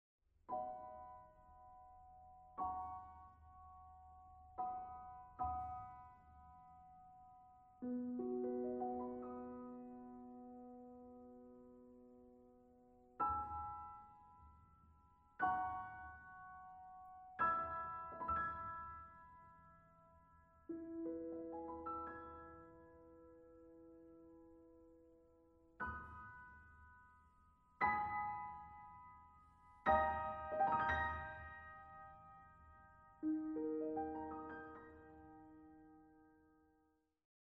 Exquisite sounds well-recorded; excellent booklet.
Piano